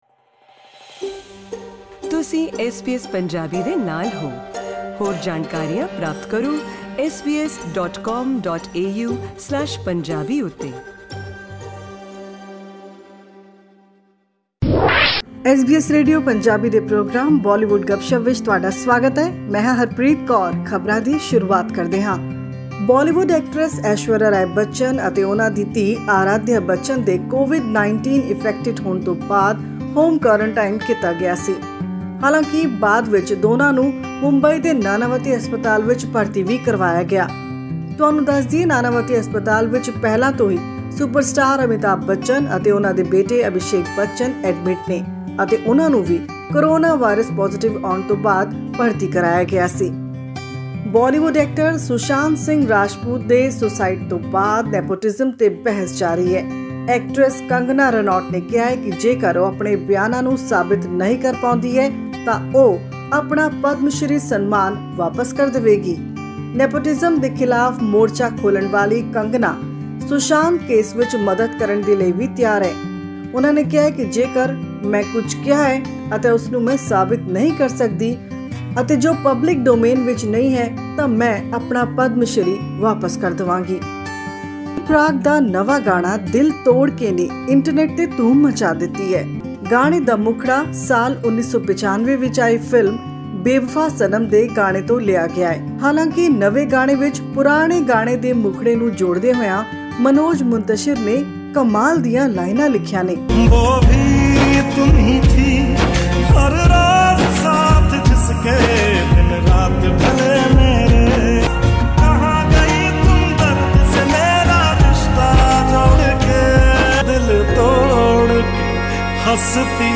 Here is our weekly news bulletin full of music, songs, and the very latest from Bollywood.